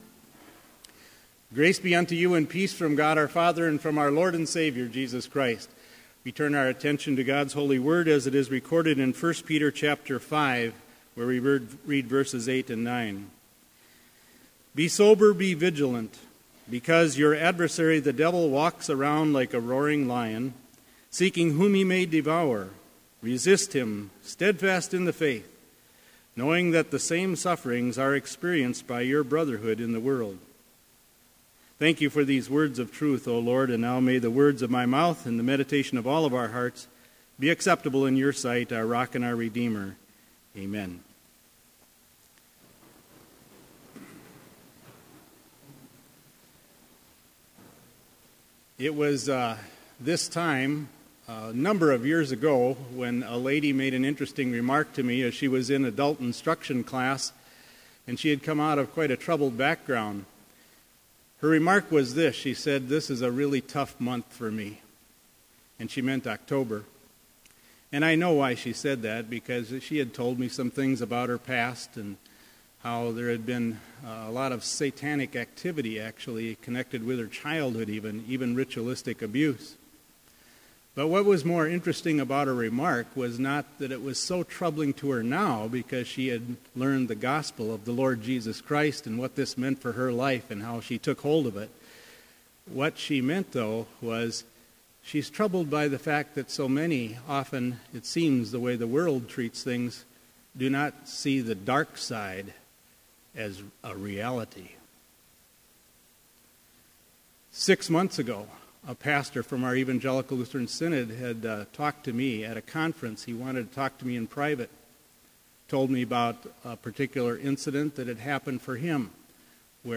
Complete service audio for Chapel - October 22, 2015
Order of Service Prelude Hymn 259, vv. 1, 3 & 5, The Kingdom Satan Founded Reading: I Peter 5:8-9 Devotion Morning Prayer Hymn 259, vv. 6 & 7, God, let not… Blessing Postlude